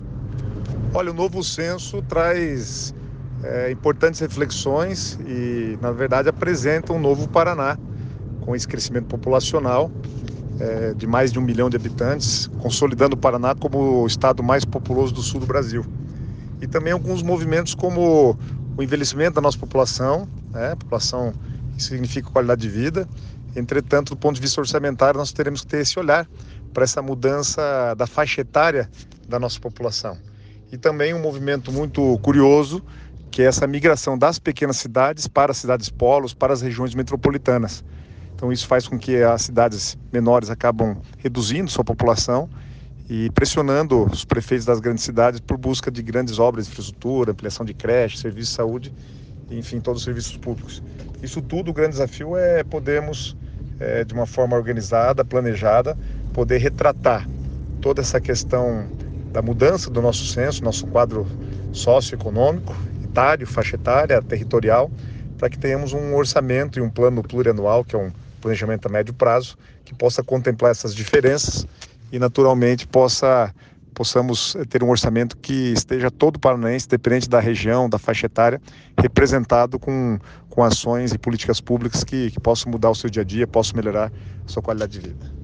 Sonora do secretário Estadual do Planejamento, Guto Silva, sobre os resultados do Censo 2022 | Governo do Estado do Paraná